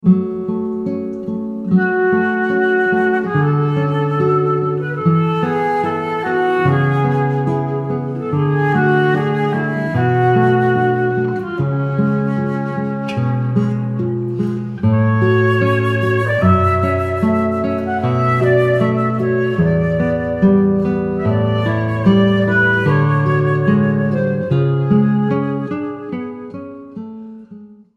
03 duo guitare flute.mp3